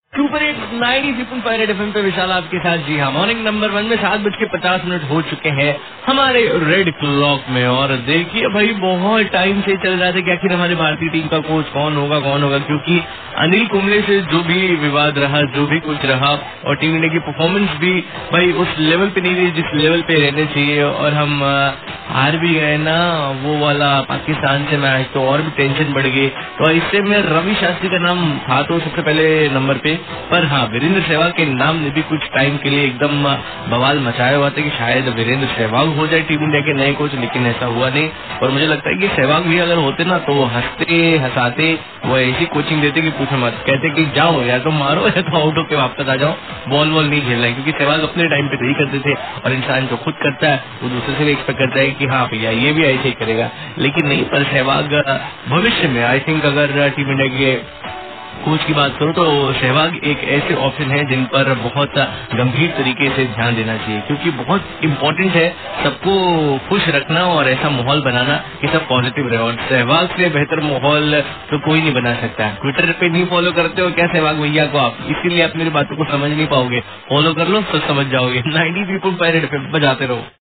RJ TALKING ABOUT RAVI SHASTRI AND SEHWAG